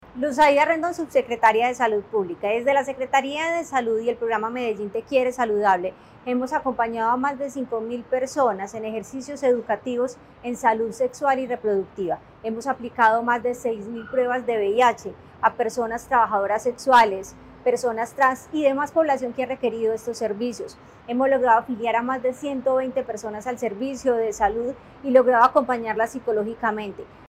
Declaraciones subsecretaria de Salud Pública, Luz Aída Rendón
Declaraciones-subsecretaria-de-Salud-Publica-Luz-Aida-Rendon.mp3